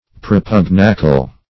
Meaning of propugnacle. propugnacle synonyms, pronunciation, spelling and more from Free Dictionary.
Propugnacle \Pro*pug"na*cle\, n.